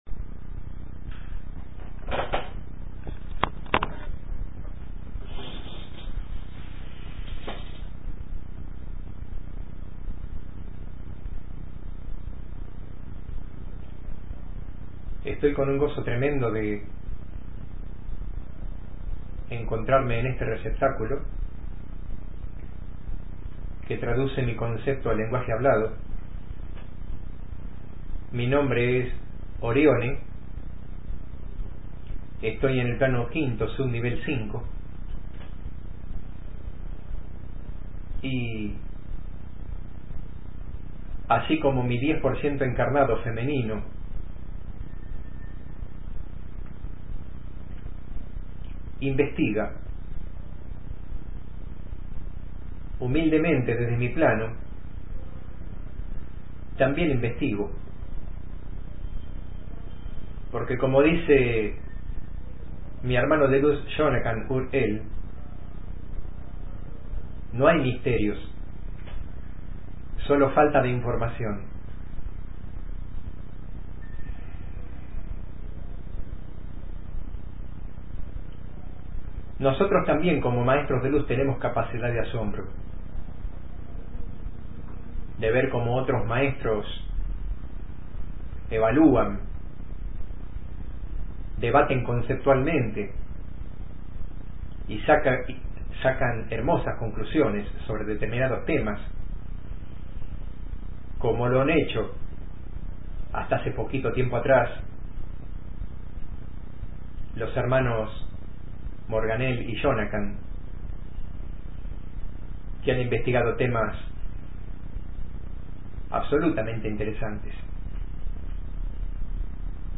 Entidad que se present? a dialogar: Maestro Orione.